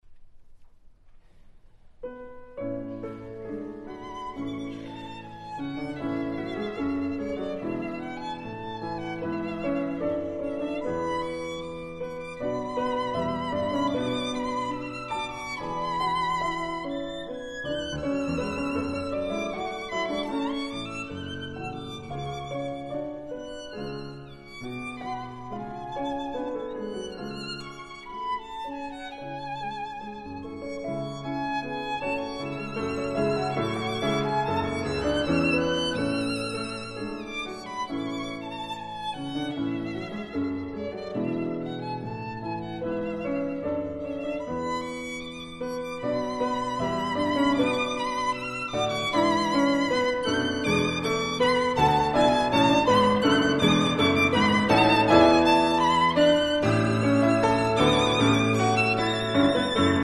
Cesar Auguste Franck - Sonate fur Violine und Klavier A-dur: 4. Allegretto poco mosso